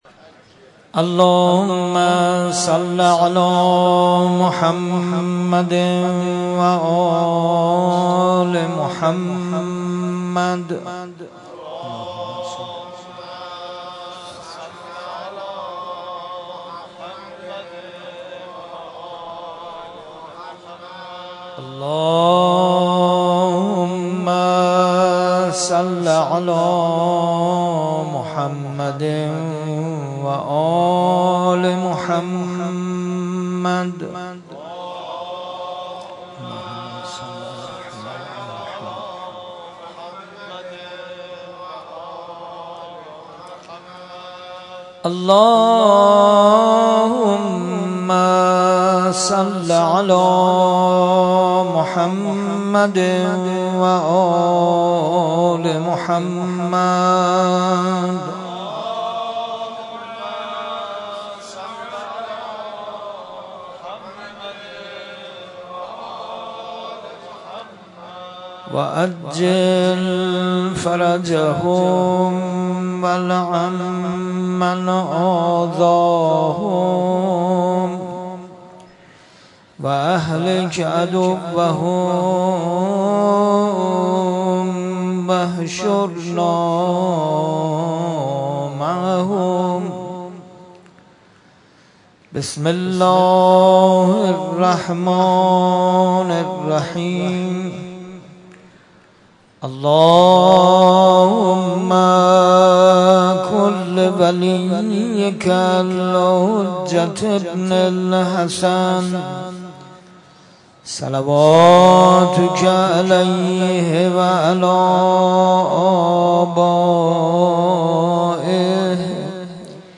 در مسجد شهدا برگزار گردید
قرائت دعای ابوحمزه (قسمت هفتم) ، روضه حضرت رقیه (علیها السلام)